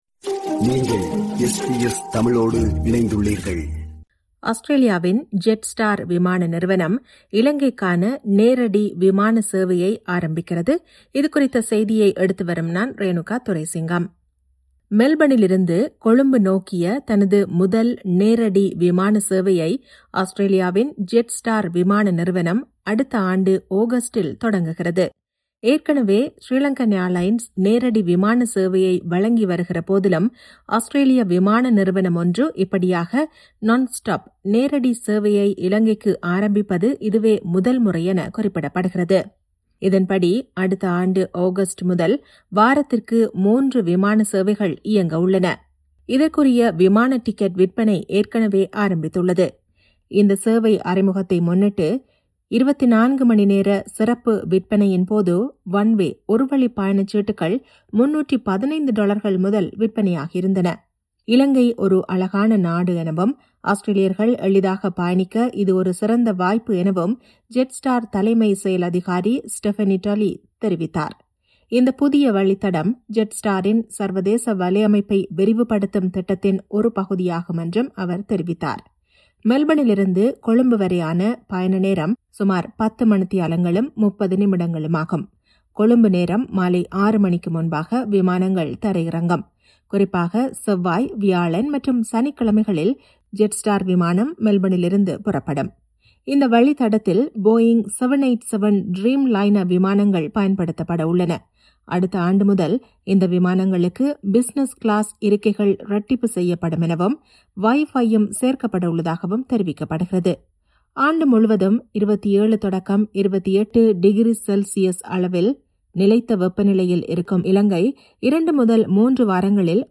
ஆஸ்திரேலியாவின் Jetstar விமான நிறுவனம், இலங்கைக்கான நேரடி விமான சேவையை ஆரம்பிக்கிறது. இதுகுறித்த செய்தியை எடுத்துவருகிறார்